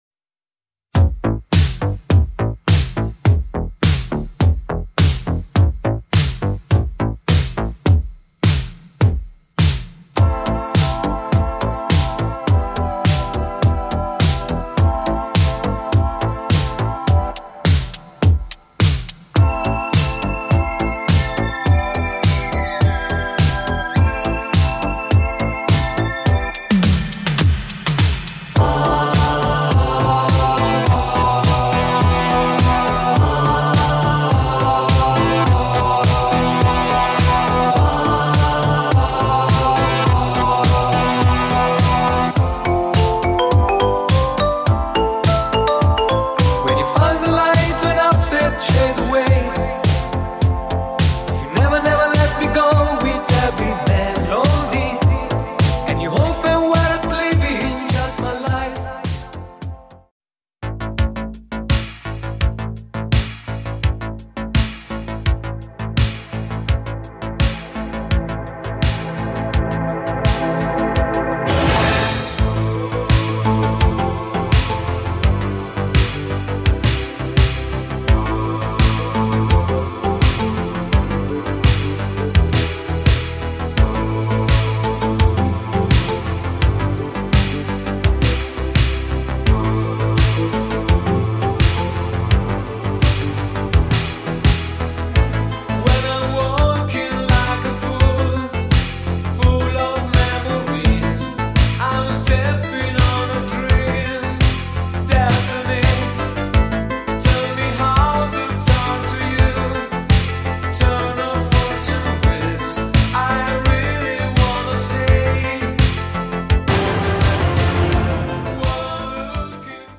um dos nomes mais emblemáticos do Italo-Disco europeu.
versões vocais, 12”, edits e um megamix exclusivo